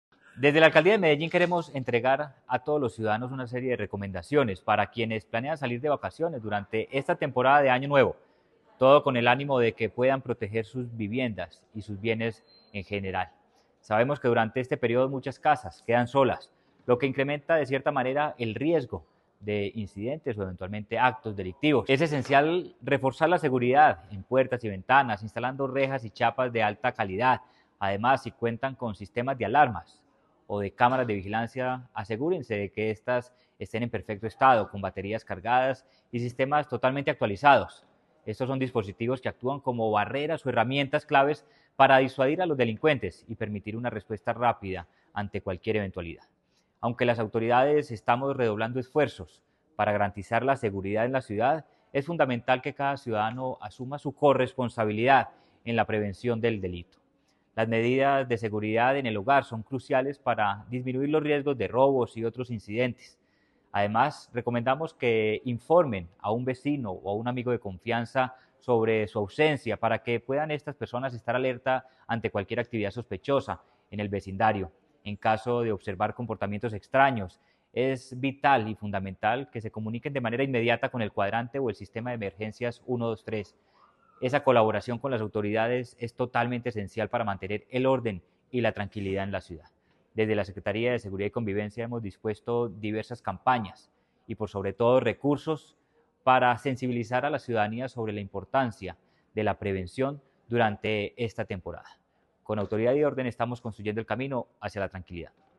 Palabras de Manuel Villa, secretario de Seguridad y Convivencia La Alcaldía de Medellín entrega recomendaciones de seguridad dirigidas a los ciudadanos que planean salir de vacaciones.